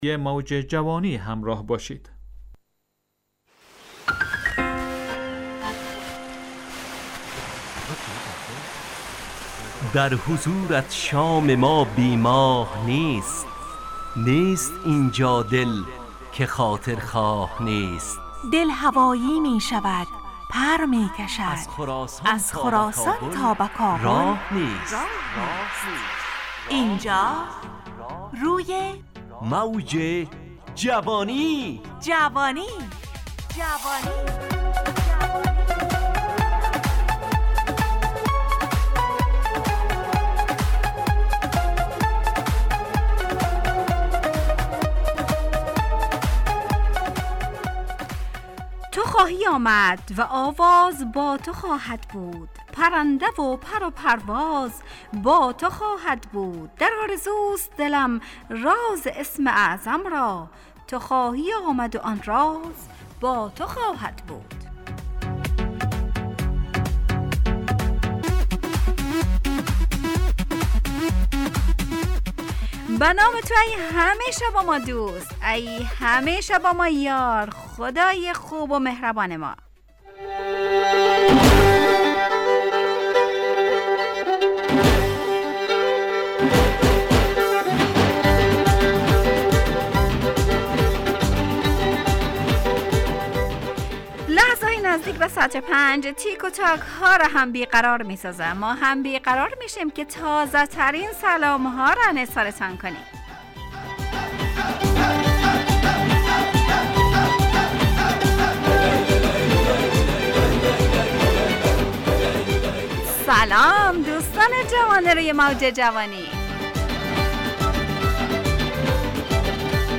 همراه با ترانه و موسیقی مدت برنامه 55 دقیقه . بحث محوری این هفته (دوستی) تهیه کننده